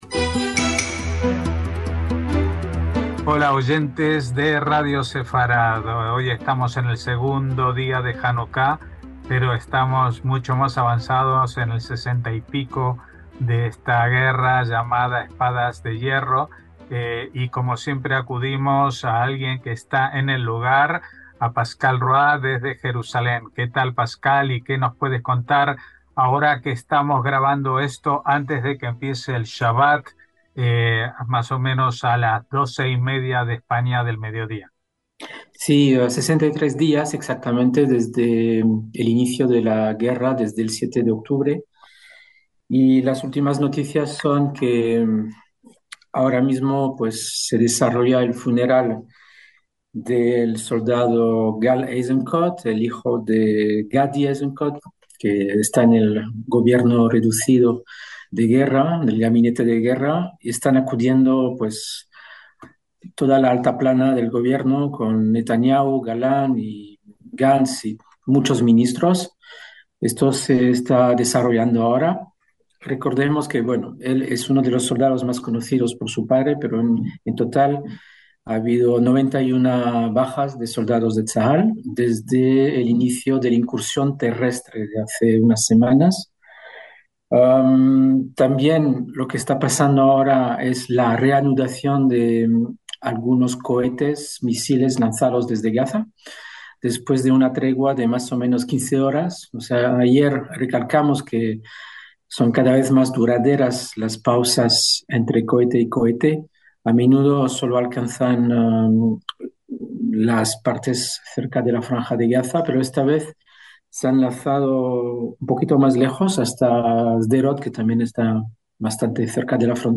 NOTICIAS CON COMENTARIO A DOS – Este segundo día de Janucá nos hace el deseo de que, como en la guerra contra los antiguos griegos, estemos cerca del final de la eliminación de la amenaza de Hamás, aunque últimamente no abunden los milagros.